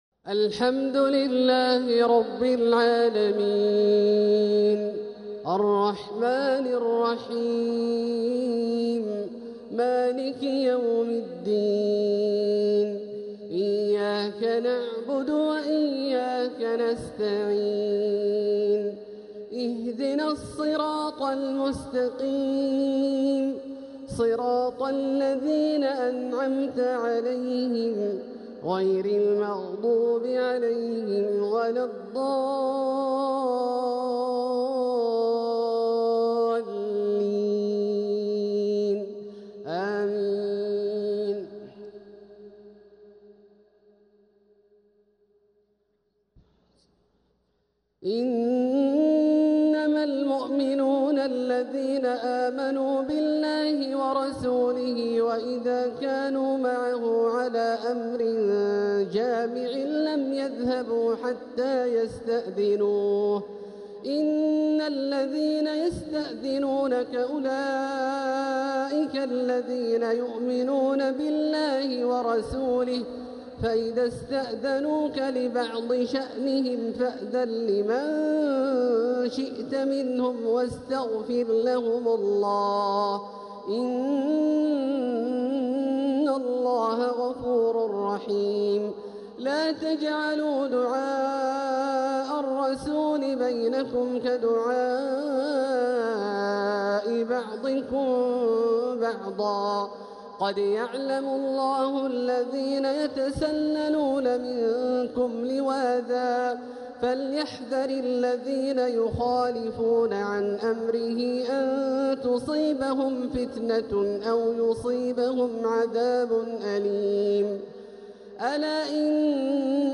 تلاوة من سورتي النور و الحجرات | عشاء الخميس 27 رمضان 1446هـ > ١٤٤٦ هـ > الفروض - تلاوات عبدالله الجهني